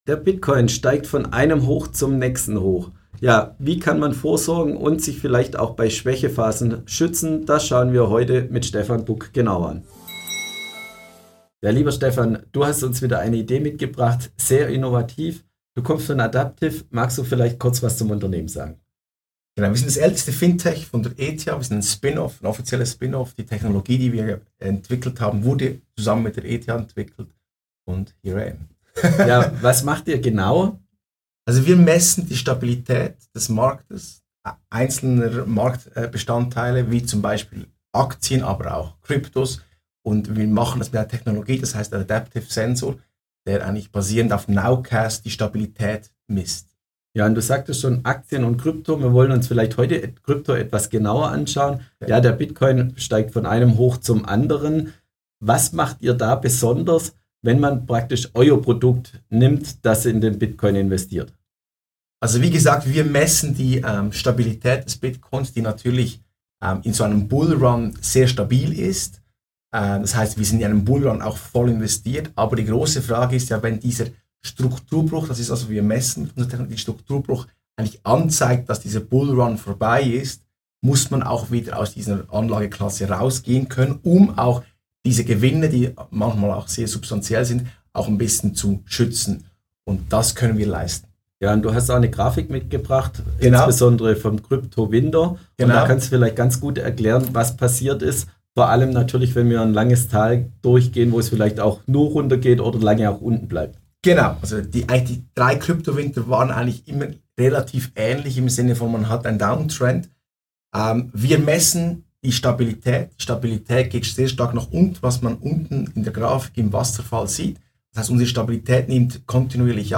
unserem heutigen Experteninterview werfen wir einen genauen Blick